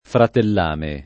fratellame [ fratell # me ] s. m.